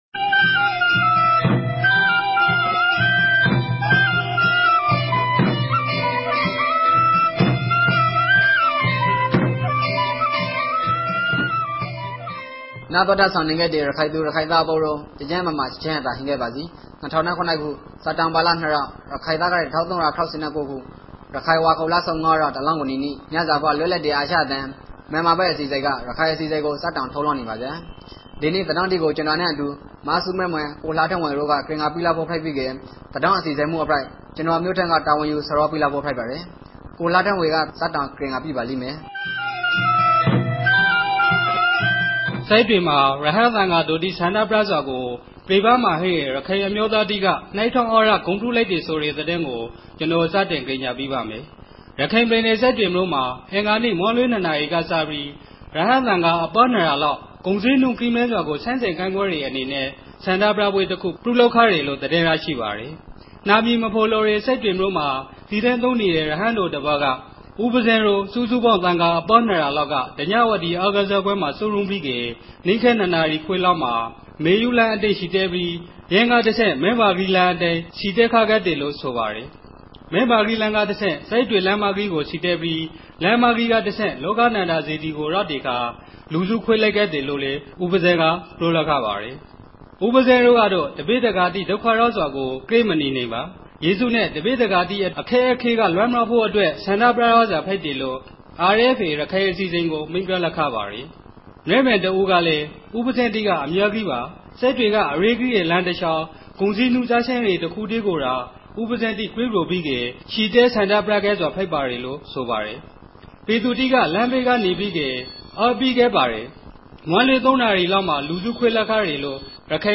ရခိုင်ဘာသာအသံလြင့်အစီအစဉ်မဵား